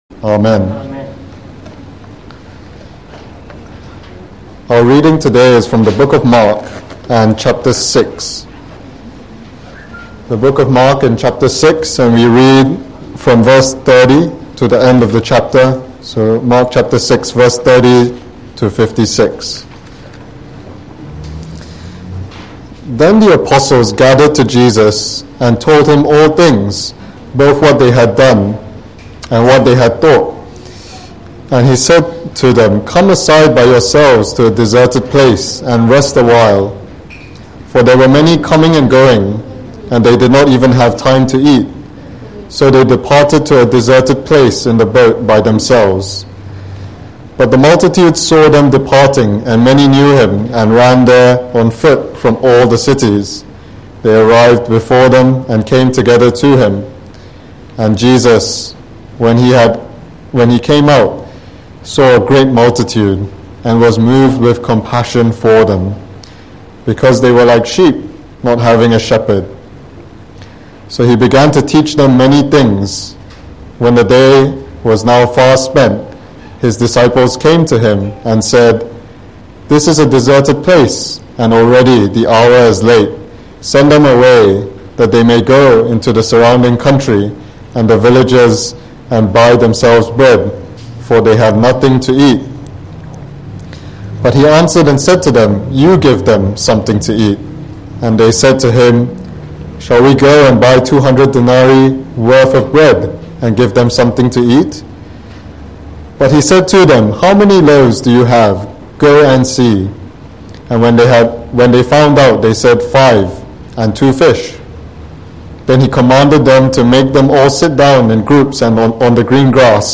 Preached on the 10 of October 2021 on the gospel of Mark delivered for the Sunday Evening Service